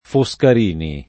Foscarini [ fo S kar & ni ] cogn.